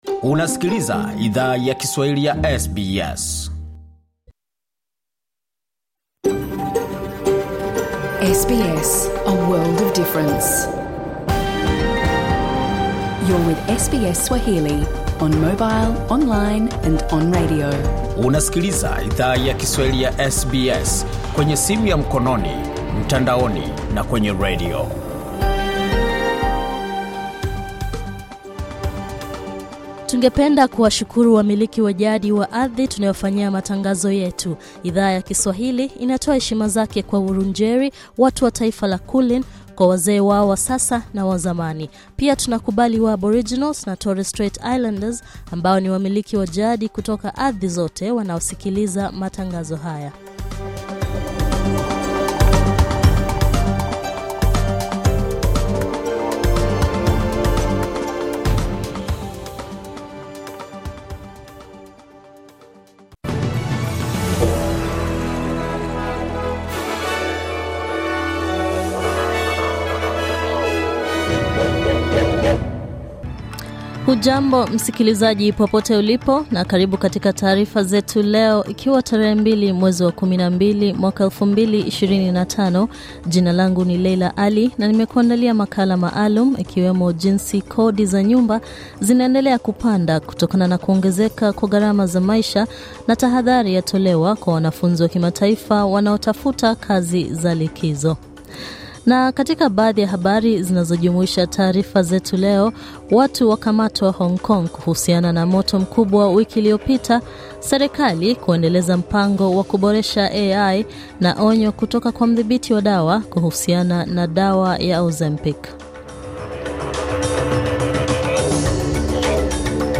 Taarifa ya habari:serikali kuendeleza mpango wa kuboresha AI nchini Australia